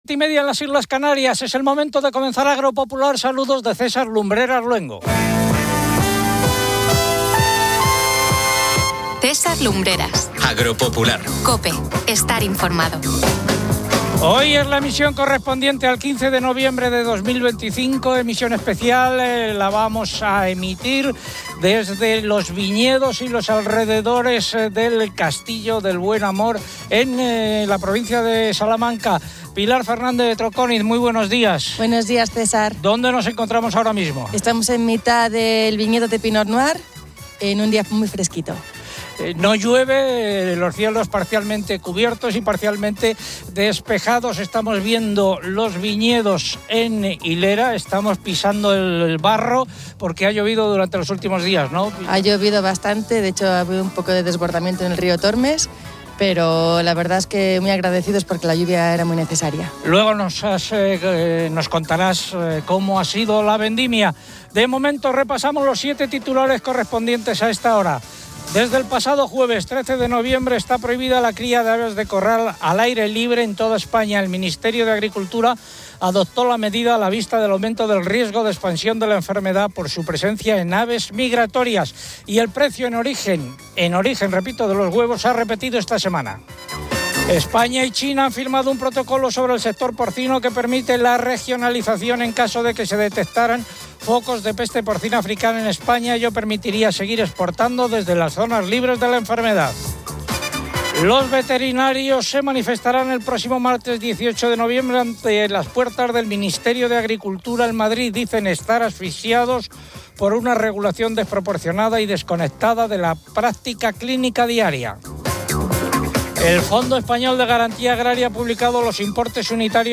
Agropopular de COPE presenta su edición del 15 de noviembre de 2025 desde los viñedos cercanos al Castillo del Buen Amor en Salamanca.